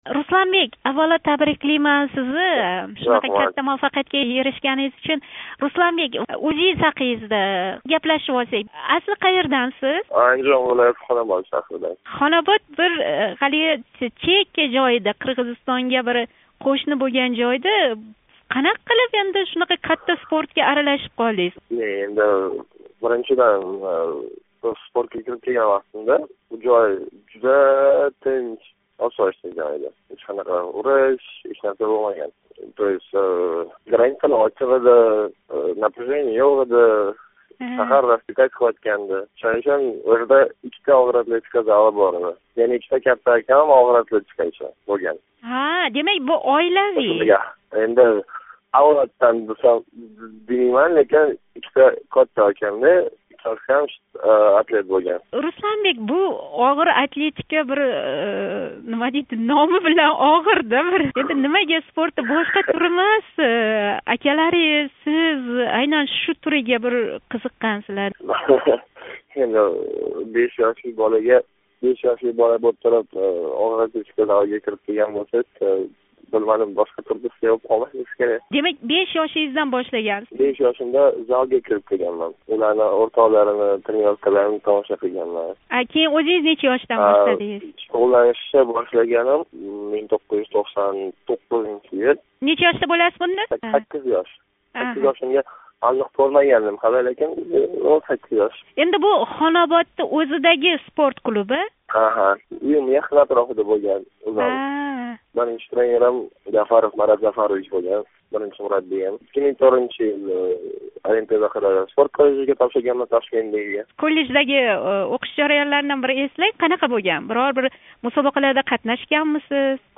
Руслан Нуриддинов билан суҳбат.